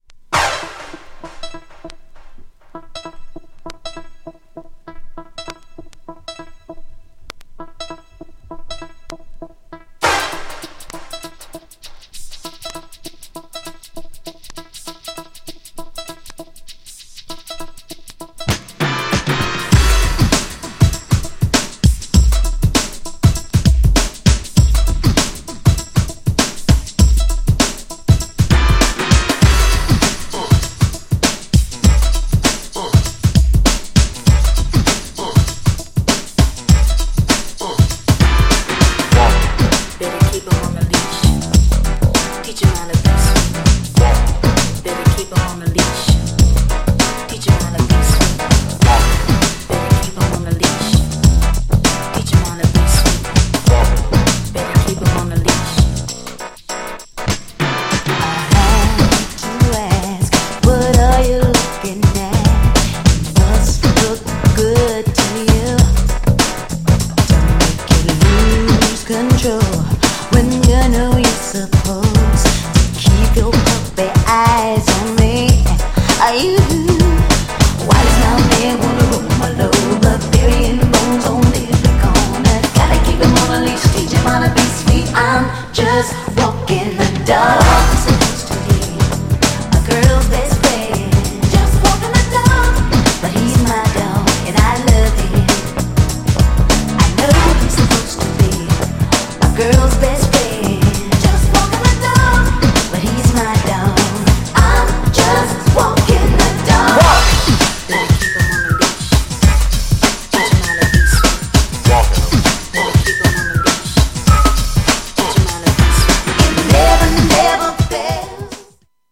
GENRE R&B
女性VOCAL_R&B